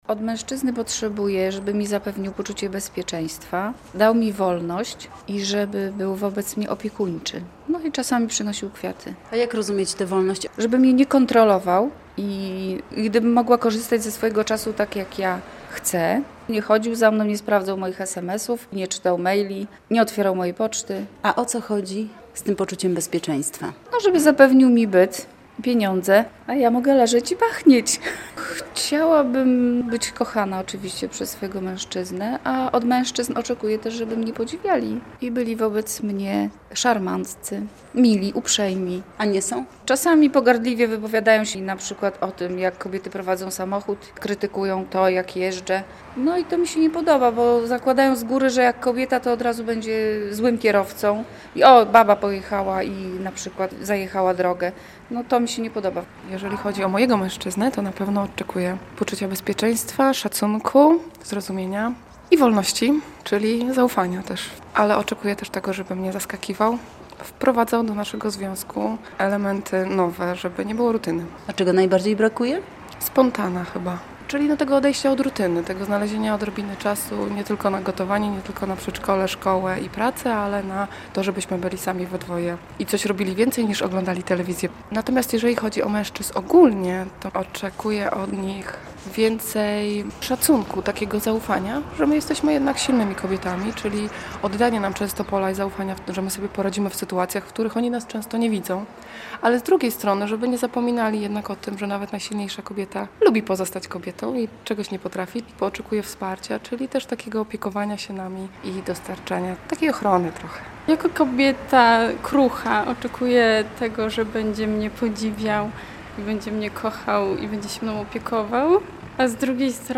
W Dniu Kobiet pytamy mieszkanki naszego regionu czego oczekują od mężczyzn - nie tylko w dniu 8 marca.